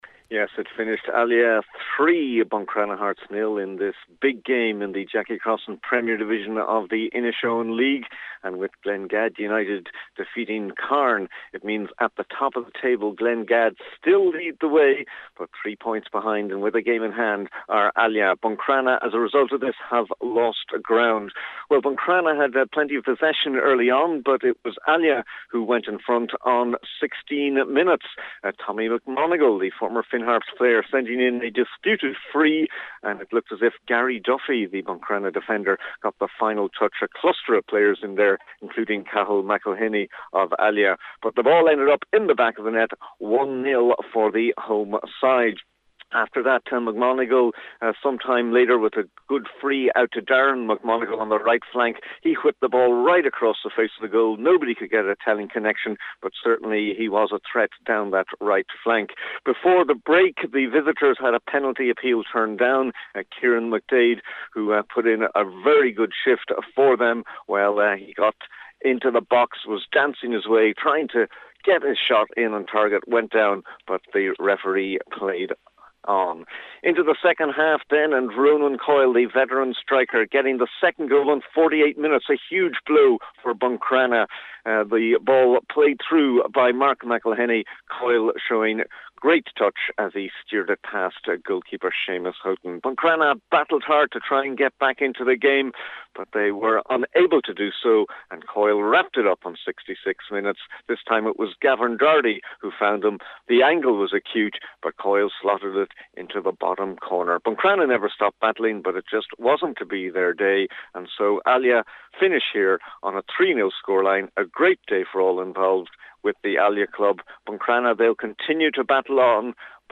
full-time report